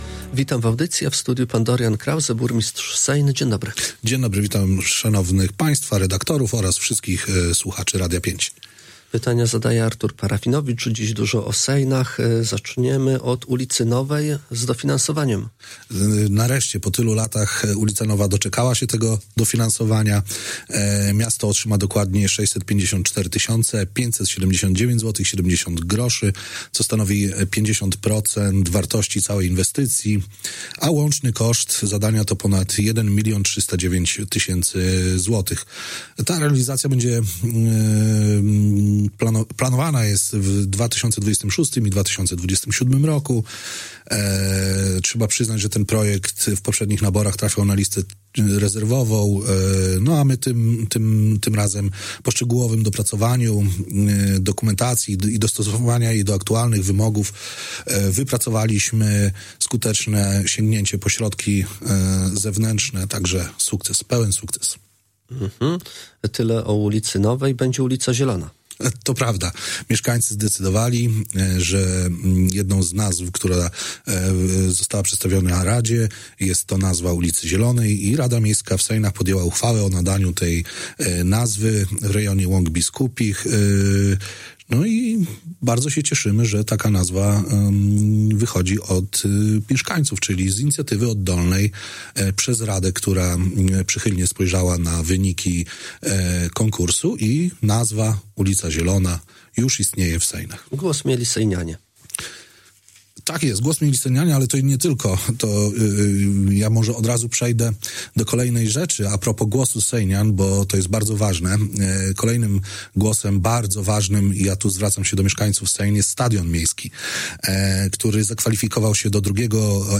O szczegółach mówił dziś w Radiu 5 Dorian Krause, burmistrz Sejn.